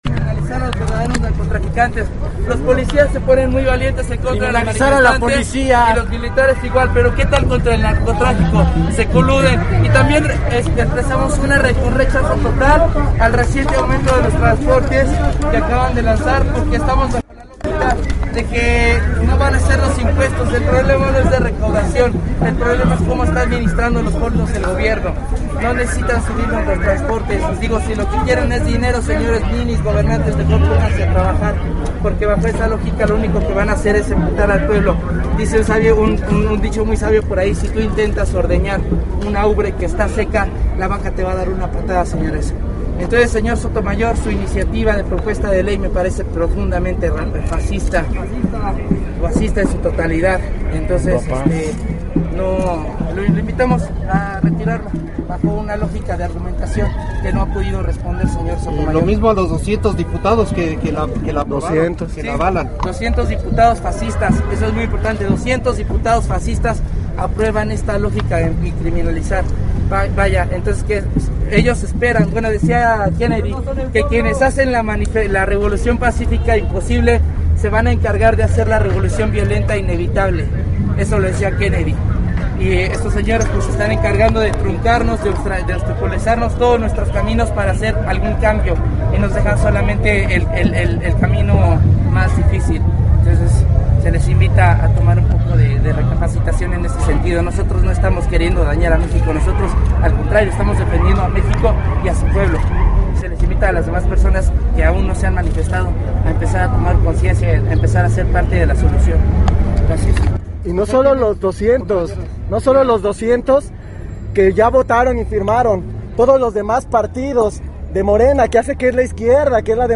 Asimismo un par de jóvenes explicaban e invitaban a sumarse a la manifestación: